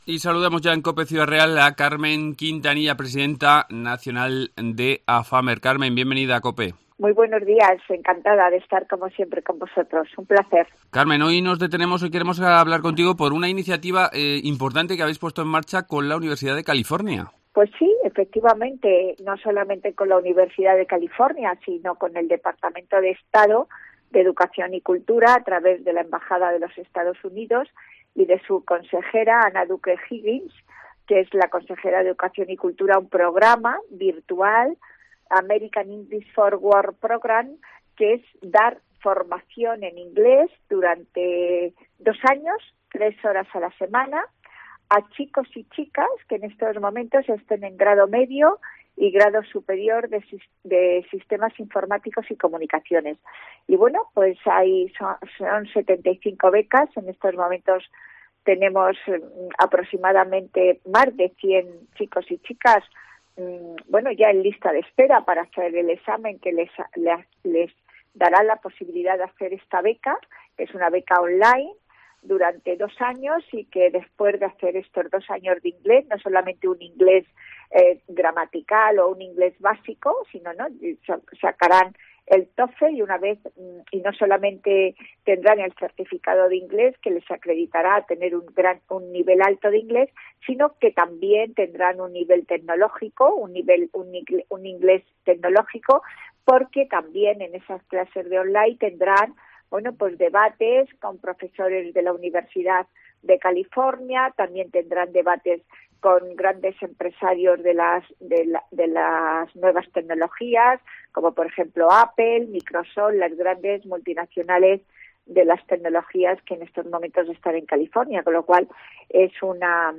Redacción digital Madrid - Publicado el 18 nov 2020, 12:04 - Actualizado 17 mar 2023, 08:34 1 min lectura Descargar Facebook Twitter Whatsapp Telegram Enviar por email Copiar enlace Hoy hemos charlado con Carmen Quintanilla que nos ha contado las últimas iniciativas de la asociación y el acuerdo rubricado con la empresa ciudadrealeña Comodonna.